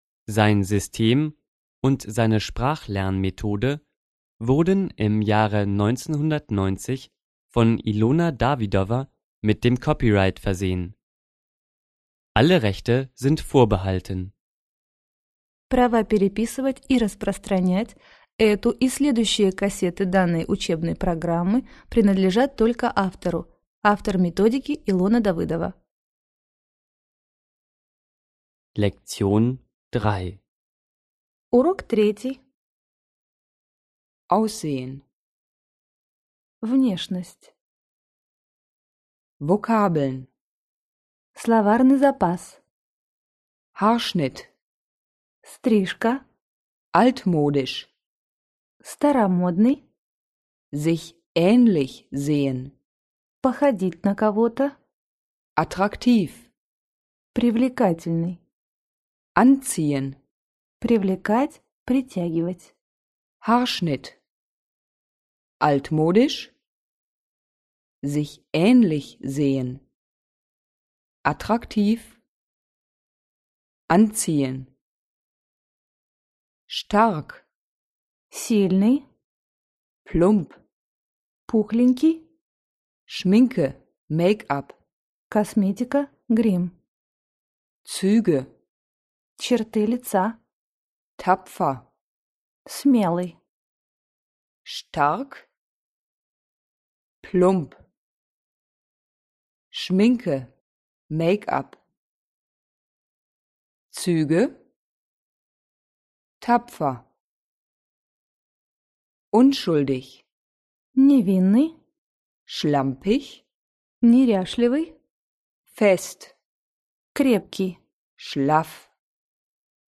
Аудиокнига Разговорно-бытовой немецкий язык. Курс 1. Диск 3 | Библиотека аудиокниг